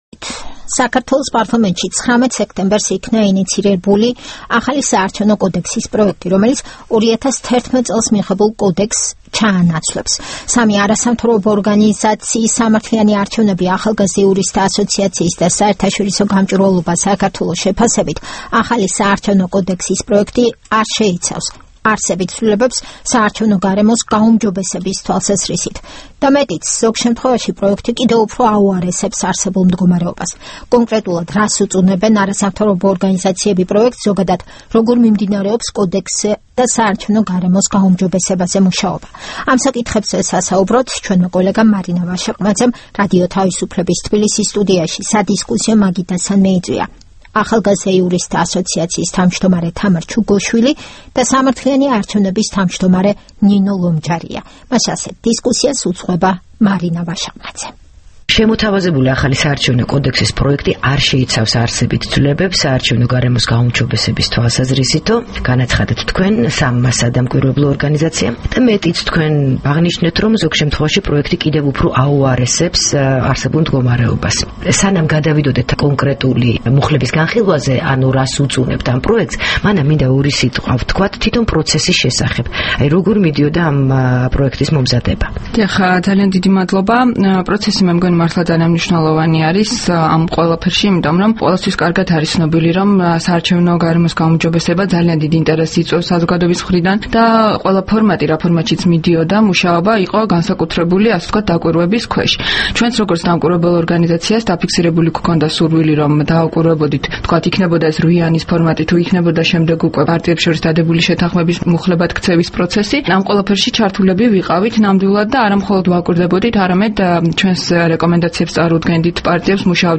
საუბარი თამარ ჩუგოშვილთან და ნინო ლომჯარიასთან